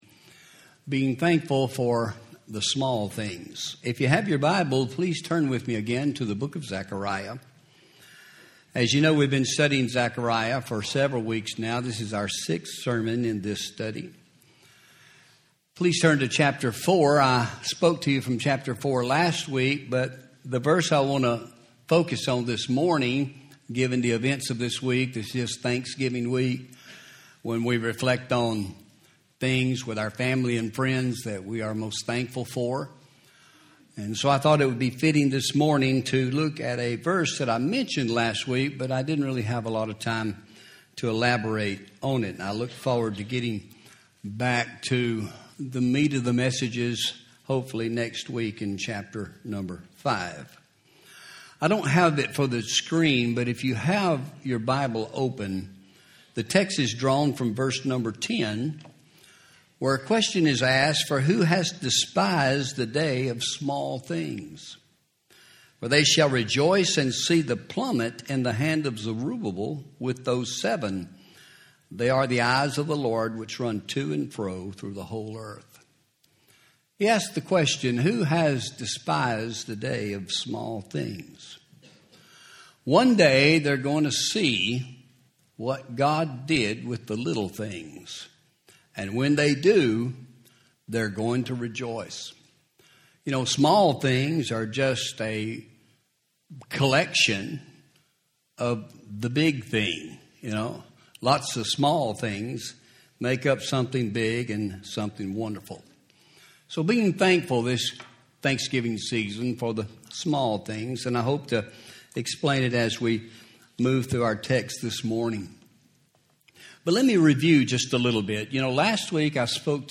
Home › Sermons › Be Thankful For The Small Things